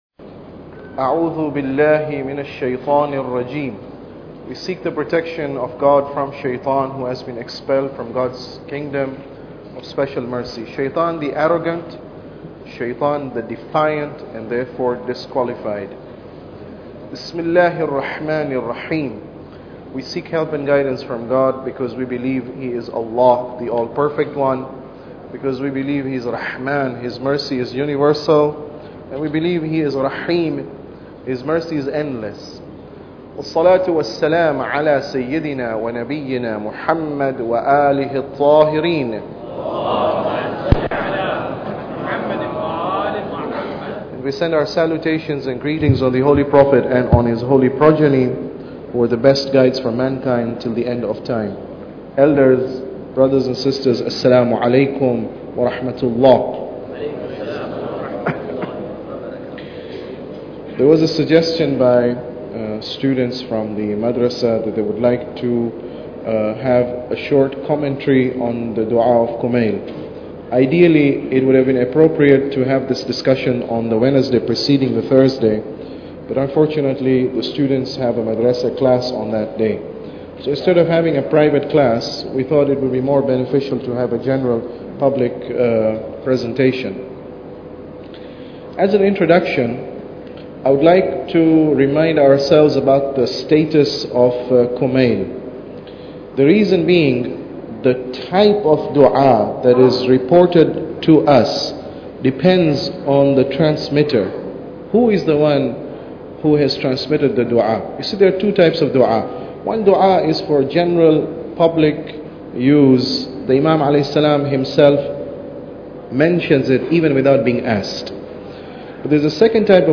Tafsir Dua Kumail Lecture 1